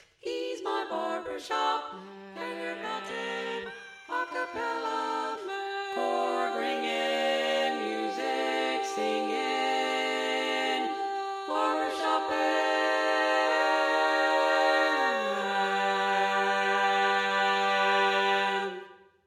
Key written in: G Major
Type: Female Barbershop (incl. SAI, HI, etc)